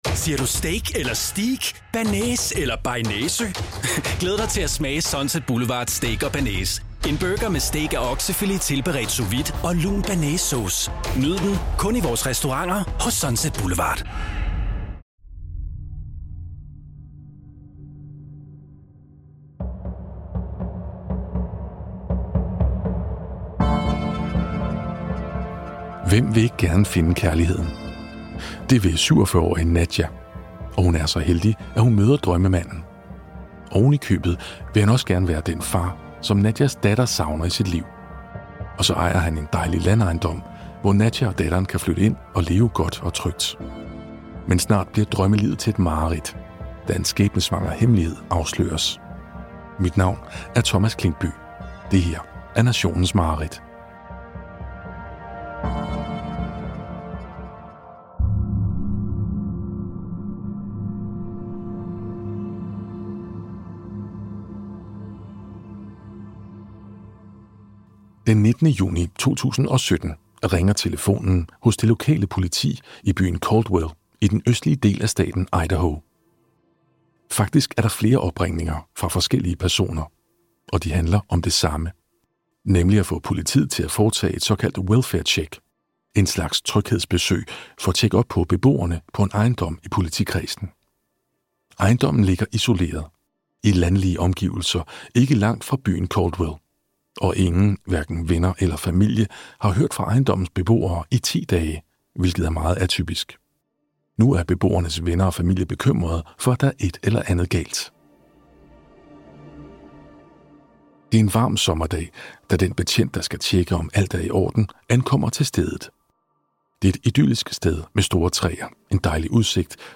Enkelte dele af fortællingerne er dramatiseret af hensyn til formidlingen.